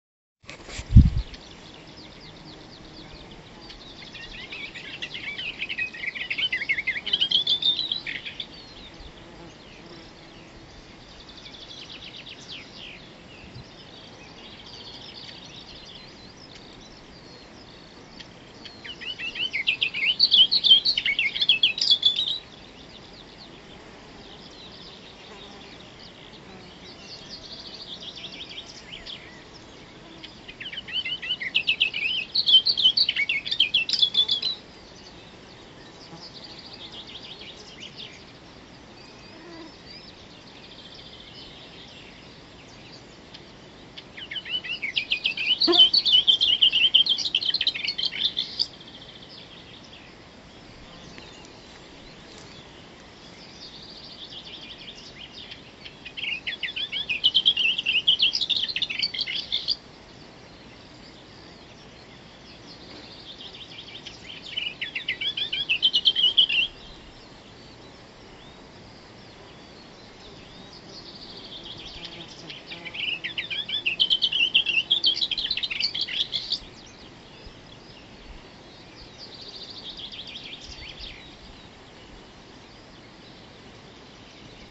Booted Warbler, Iduna caligata
StatusSinging male in breeding season
Notes Dzied pļavas vidū ābeļu pudurī.